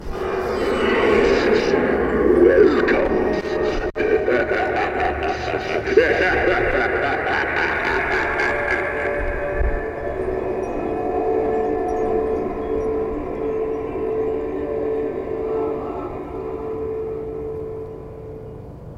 books-just this last fall laugh.mp3